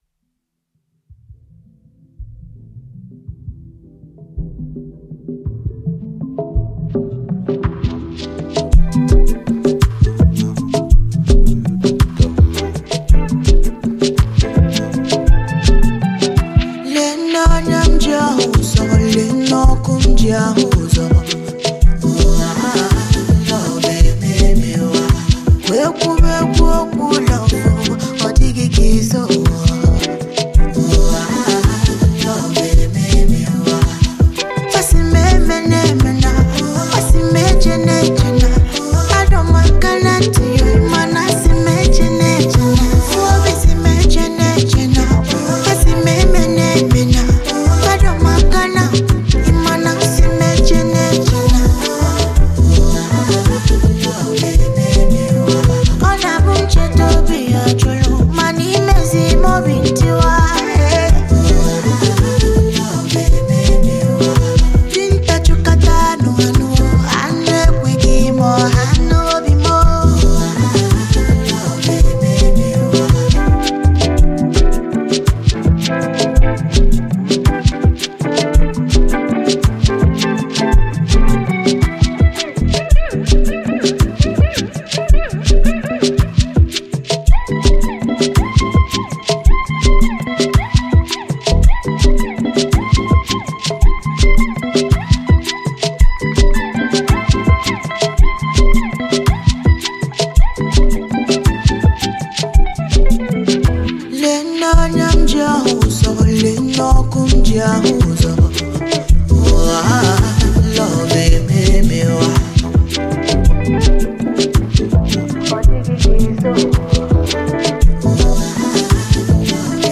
Soundtrack Album
Afrobeats